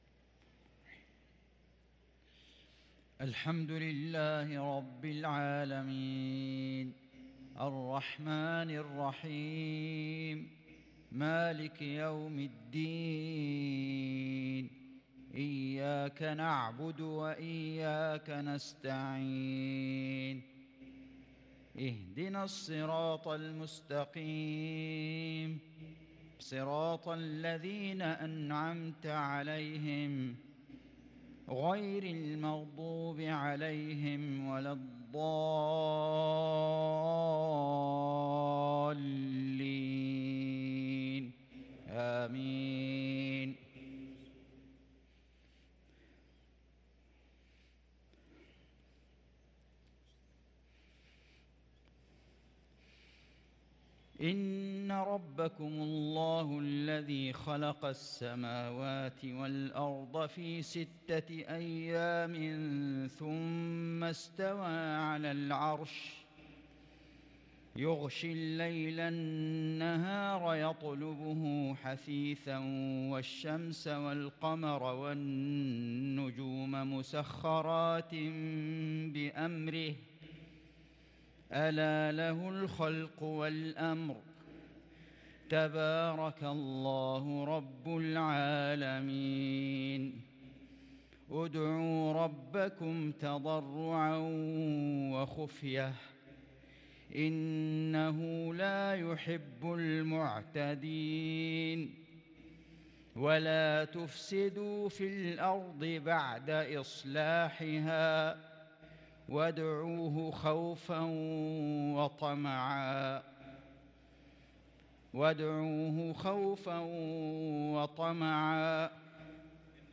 صلاة المغرب للقارئ فيصل غزاوي 20 جمادي الأول 1444 هـ
تِلَاوَات الْحَرَمَيْن .